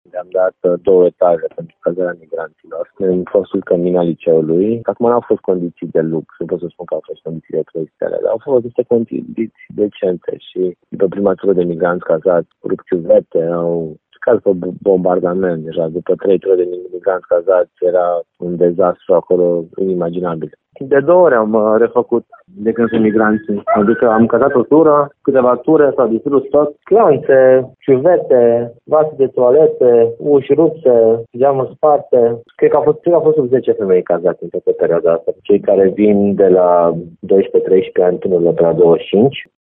Din decembrie şi până acum, Primăria Timişoara a fost nevoită să facă mai multe lucrări de reparaţii, ca urmare a stricăciunilor făcute de migranţi, spune viceprimarul Ruben Laţcău.
Ruben-Latcau-migranti.mp3